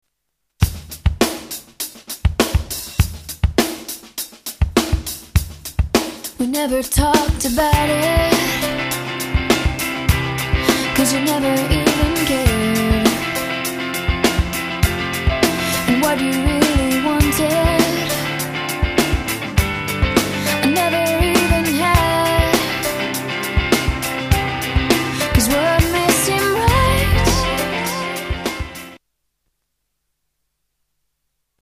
STYLE: Rock
powerful ballads